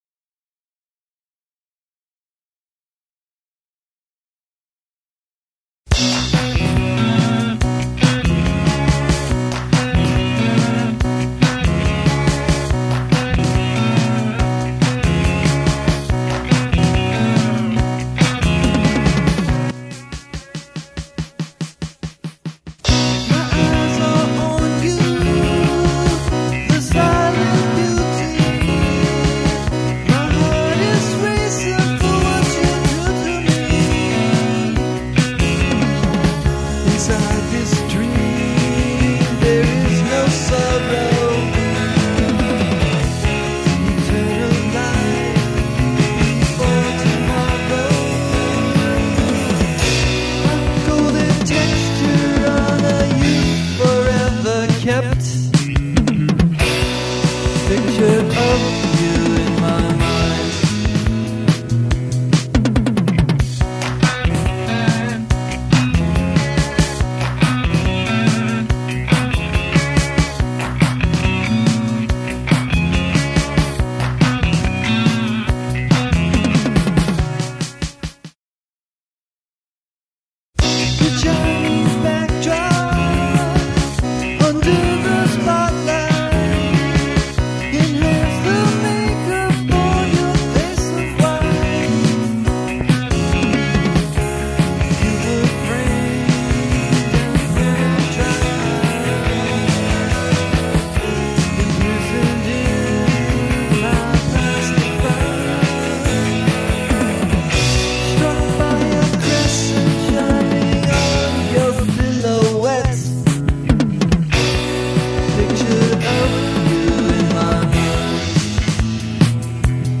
guitar, bass guitars, Chapman Stick Touchboard
percussion
guitar solo